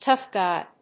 アクセントtóugh gúy